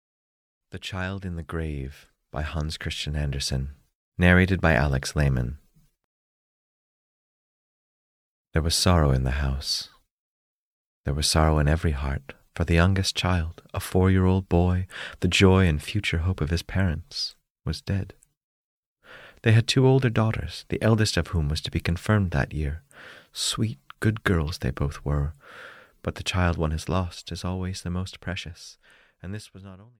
The Child in the Grave (EN) audiokniha
Ukázka z knihy